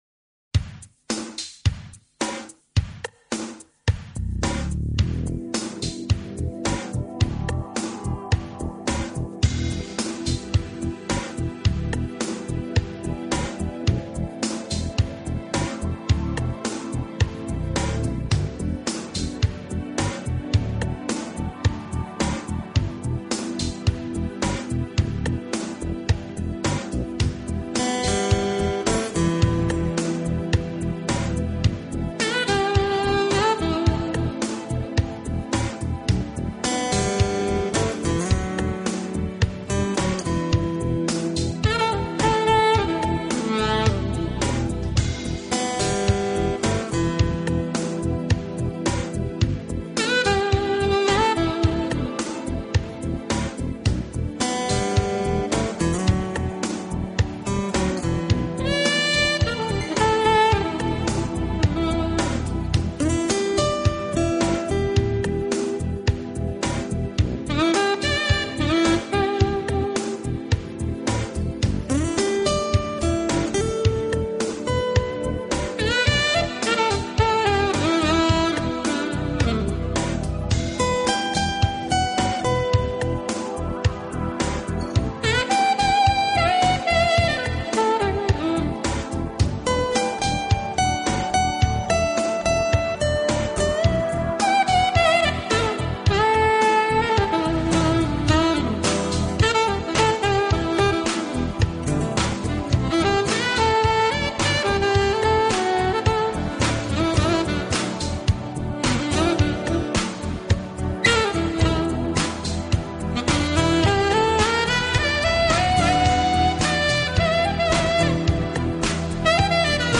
乐文化，Down Tempo是大部份的特色，范围涵盖了Jazz、Blue、Classic、
共同点都是带给人們Relax，还有Beautiful的感觉。